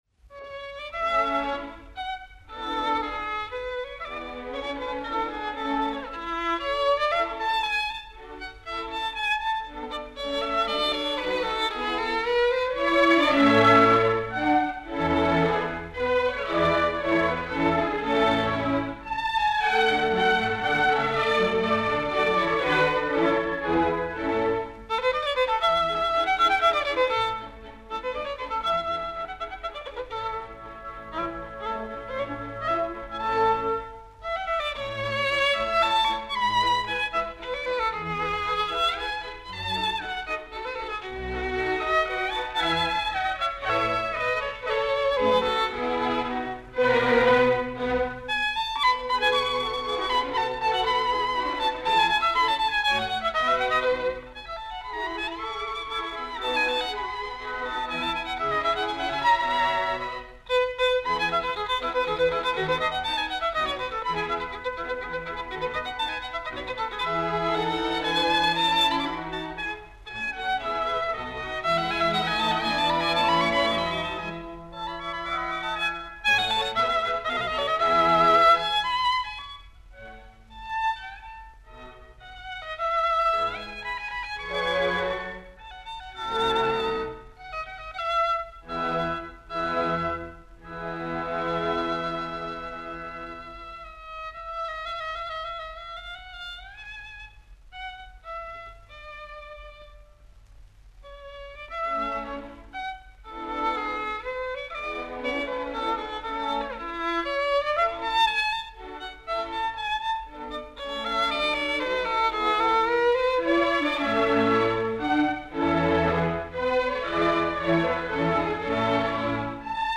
The interjection of the ‘temperamental and gruff’ in a minor key really breaks up the introspection of the minuet.
Russian violinist
Performed by
Soviet State Orchestra
MOZART-CONCERTO-POUR-VIOLON-K.-219-RONDEAU.mp3